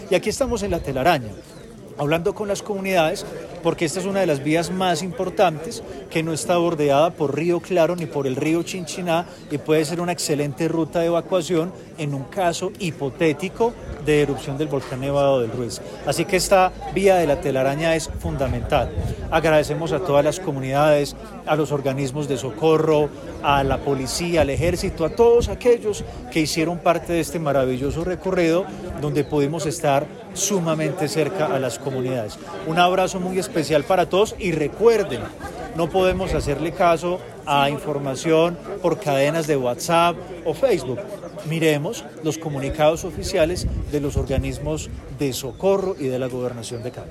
Audio_gobernador_de_Caldas_Volcan_vias_.mp3